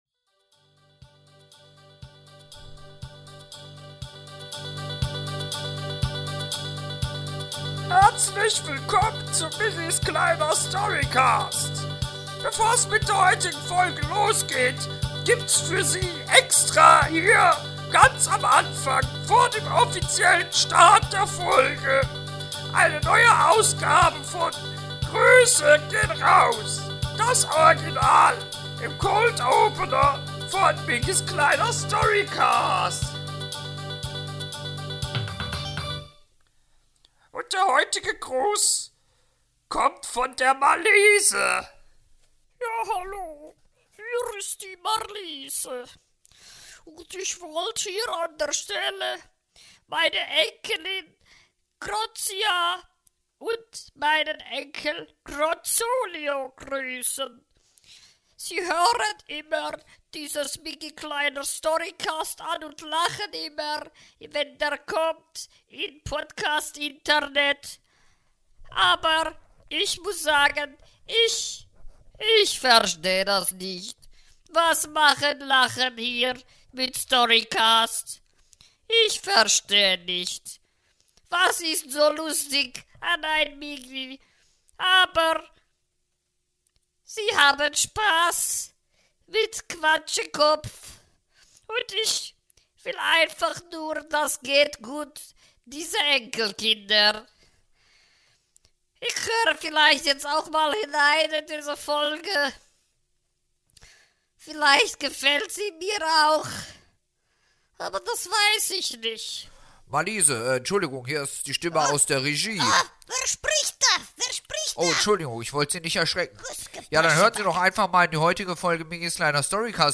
Heute nimmt er sich wieder seinen alten Schinken "Herribert Krumm" zur Brust und rezitiert die Szenen 4, 5 und 6.